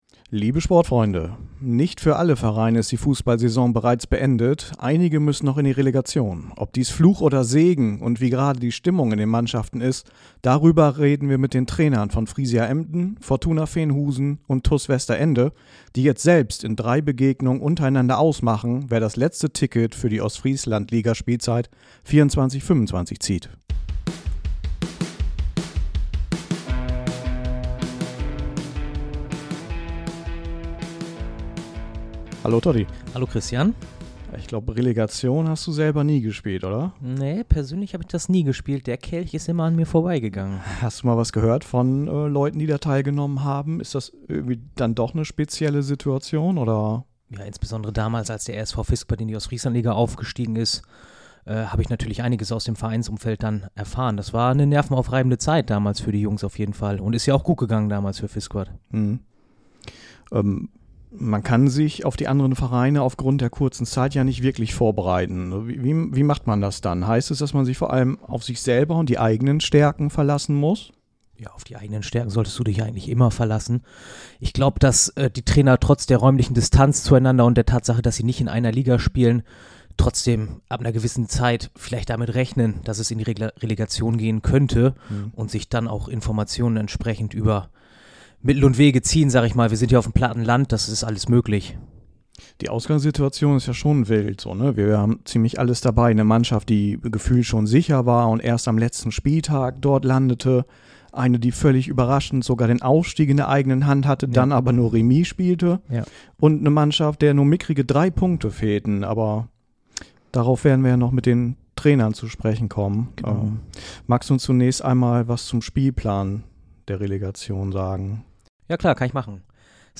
Drei Trainer im Gespräch über Fluch und Segen der Relegation, die Stimmung in der eigenen Mannschaft und ihren Blick auf die Gegner.